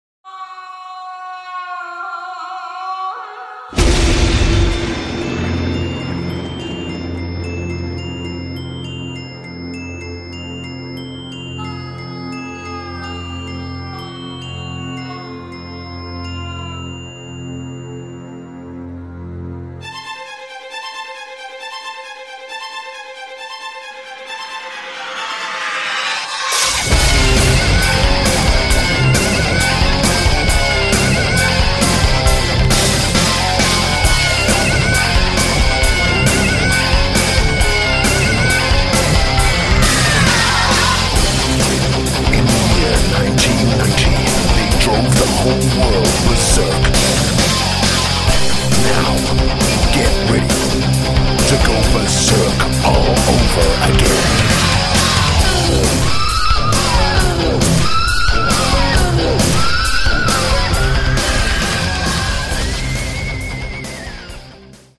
Category: Glam
Vocals
Bass
Guitar
Drums
Keyboards